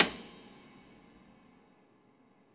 53a-pno26-C6.aif